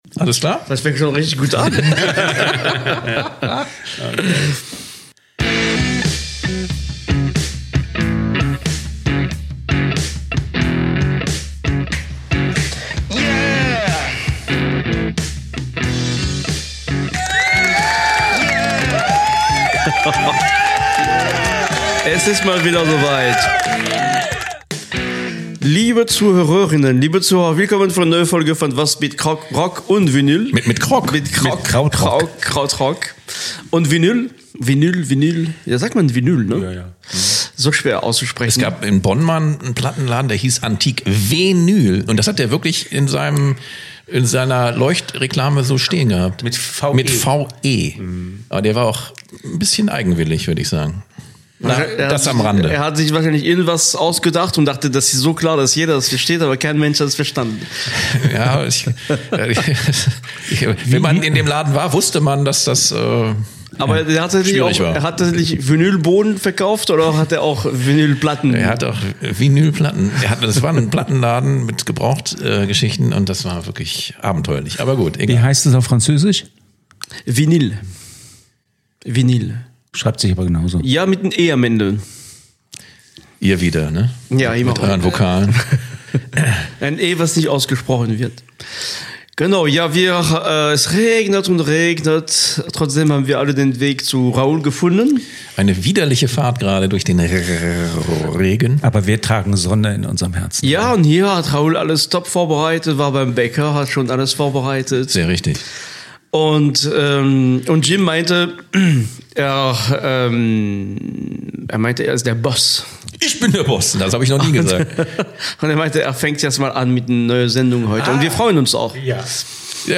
#224 Pocket Full Of Hits – Indie Pop, Garage and Pschedelic Rock